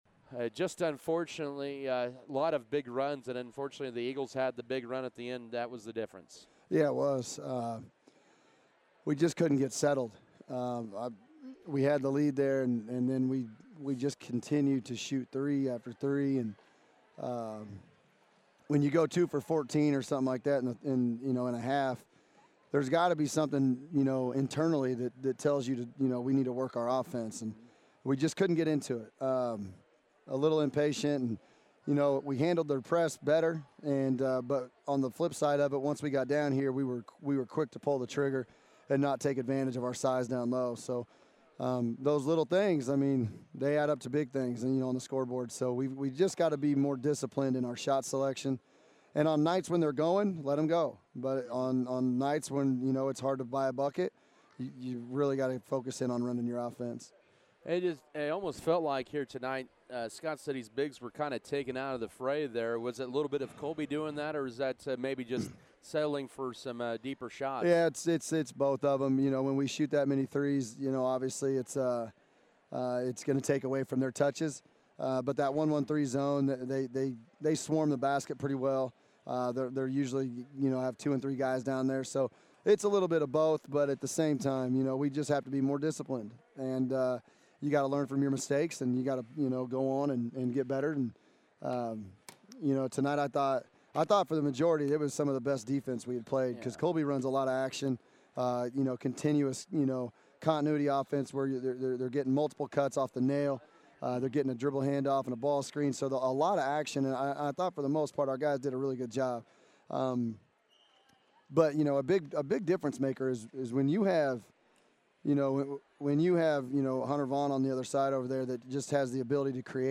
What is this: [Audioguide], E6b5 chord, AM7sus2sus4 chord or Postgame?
Postgame